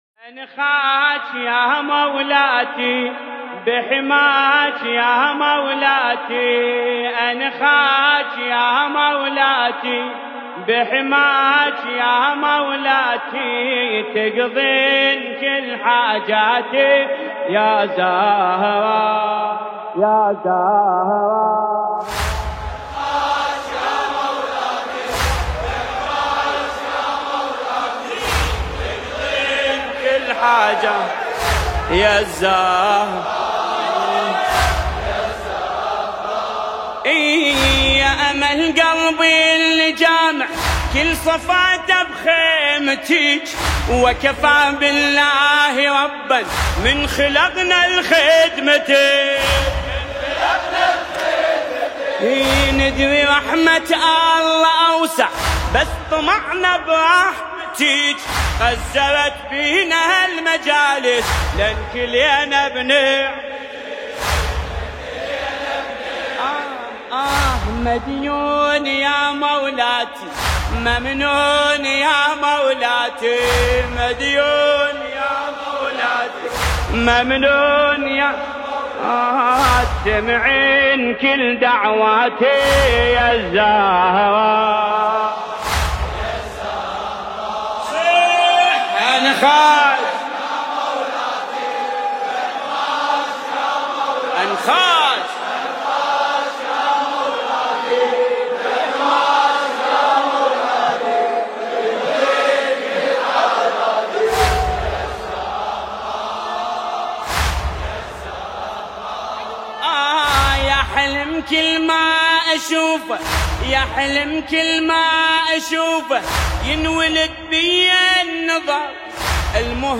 نوحه دلنشین